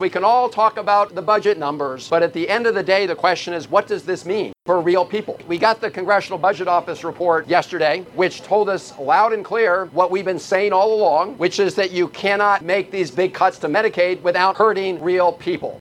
US Senator Chris Van Hollen spoke at a vigil in front of the US Capitol with caregivers, healthcare advocates, and beneficiaries about proposed cuts to federal Medicaid spending. Over $880 billion dollars in cuts is being suggested over the next decade that would affect over 71 million Americans.